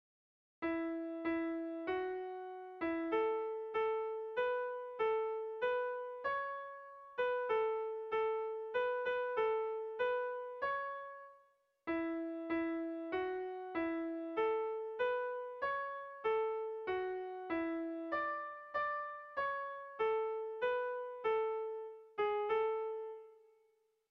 Irrizkoa
Zuberoa < Euskal Herria
Lauko handia (hg) / Bi puntuko handia (ip)
A1A2